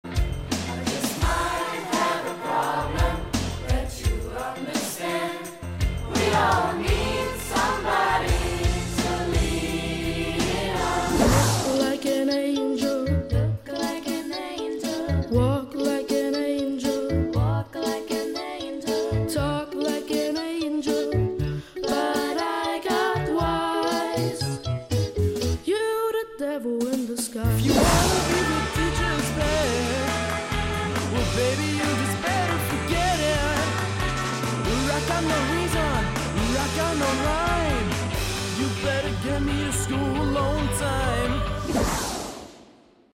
250 Schüler aus Essen geben ein XXL Konzert
Seit 32 Jahren wird in Essen die Turnhalle einer Schule zur Konzerthalle. Auch jetzt stehen wieder 250 Schüler auf der Bühne.
schulkonzert.mp3